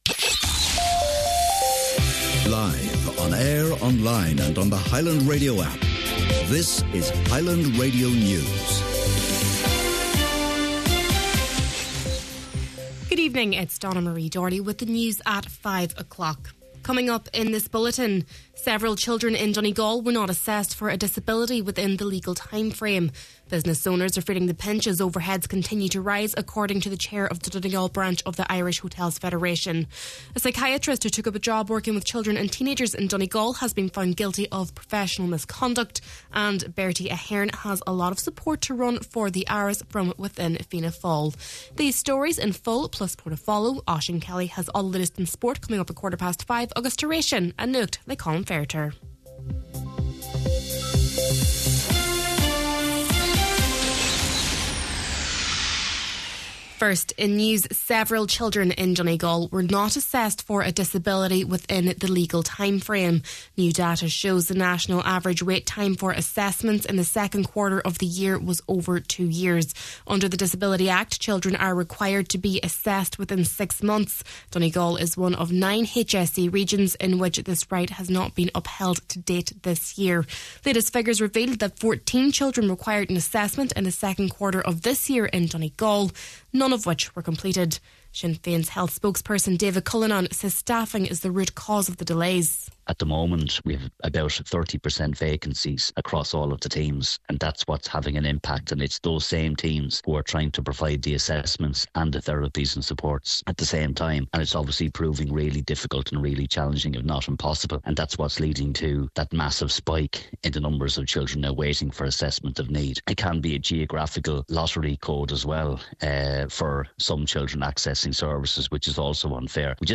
Main Evening News, Sport, An Nuacht and Obituaries – Friday, August 22nd